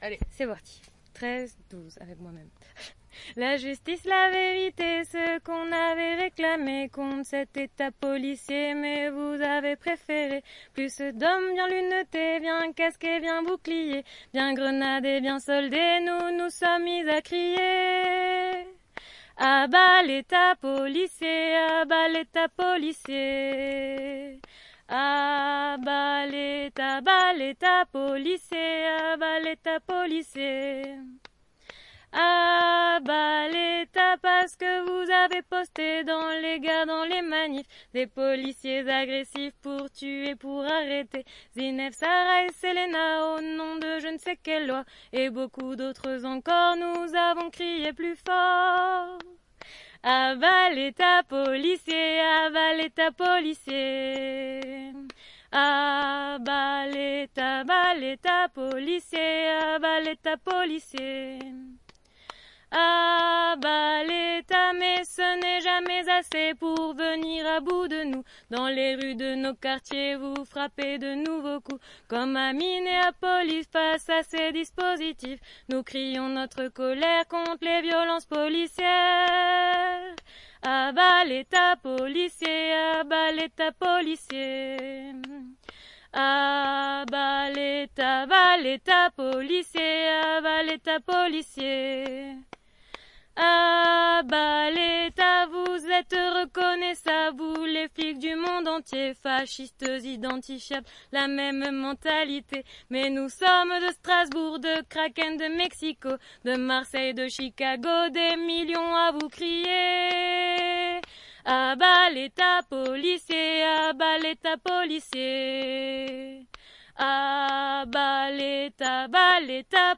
Choeur de la Mascarade - a-bas-letat-voix-haute.mp3
Root A bas l'état policier // Choeur des louves a-bas-letat-voix-haute.mp3